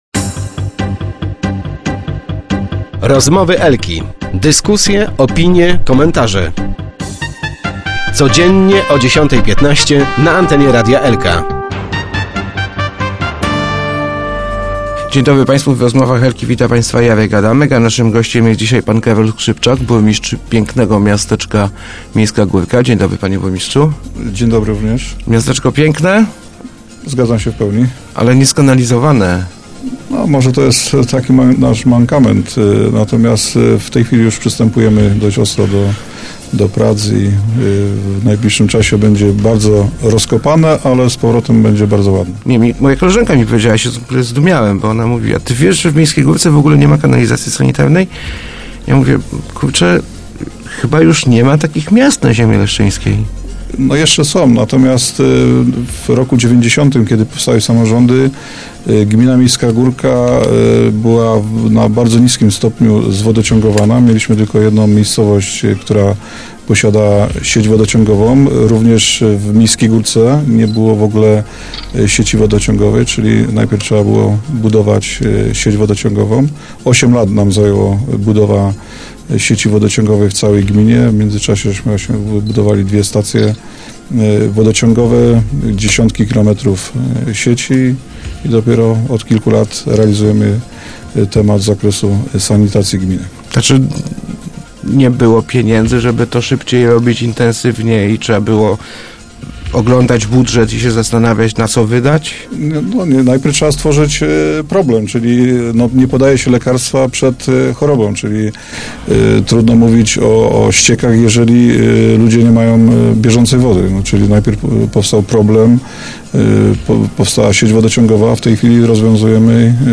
skrzypczak80.jpgZa dwa, trzy lata Miejska Górka powinna być skanalizowana – mówił w Rozmowach Elki burmistrz Karol Skrzypczak.